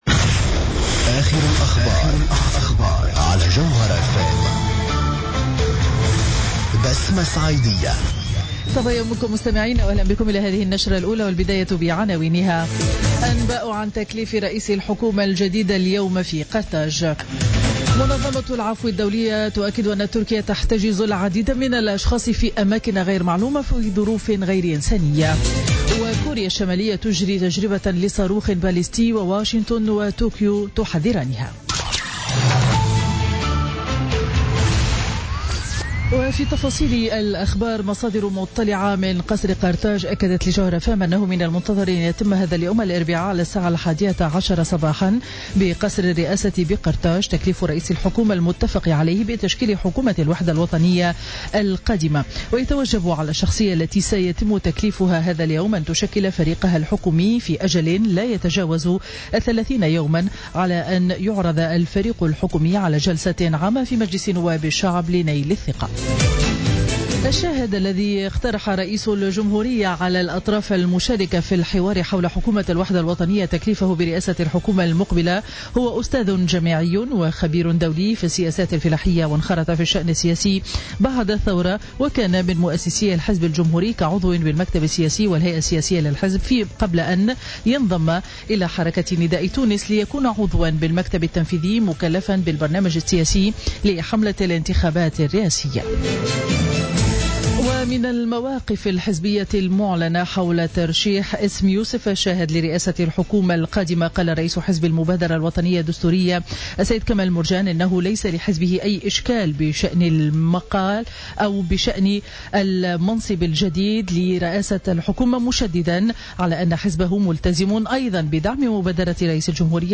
نشرة أخبار السابعة صباحا ليوم الاربعاء 3 أوت 2016